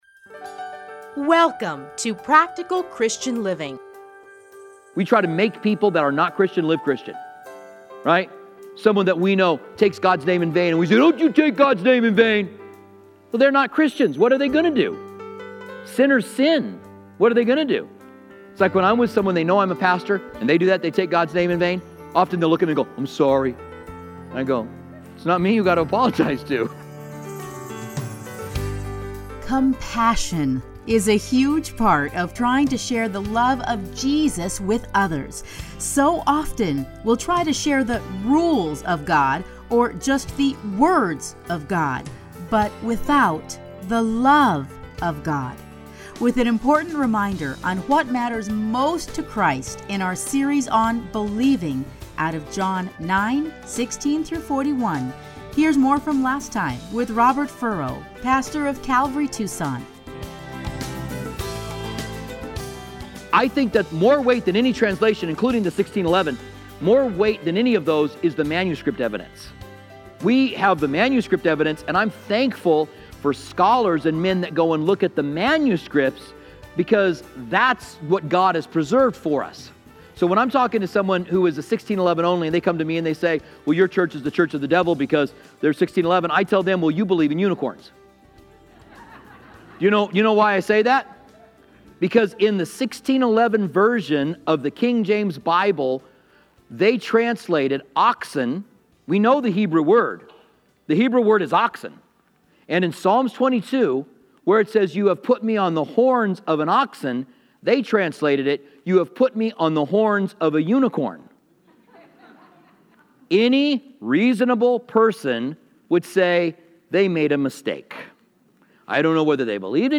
teachings are edited into 30-minute radio programs titled Practical Christian Living